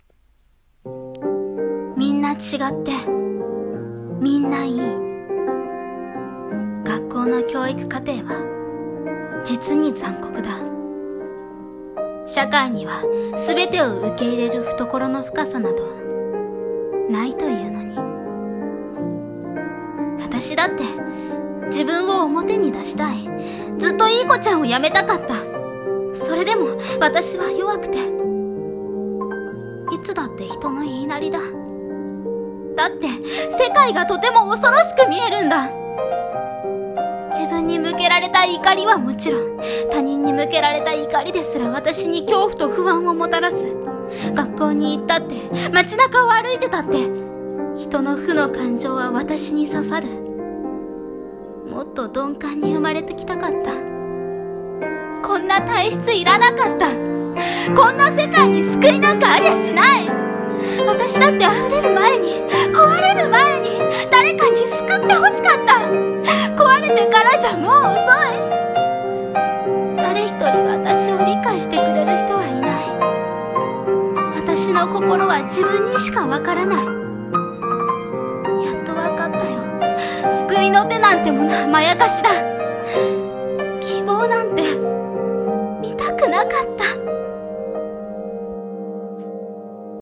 【声劇台本】希望なんて見たくなかった